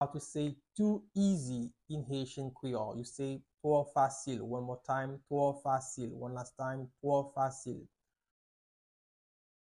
Pronunciation:
15.How-to-say-Too-Easy-in-Haitian-Creole-–-Two-fasil-pronunciation.mp3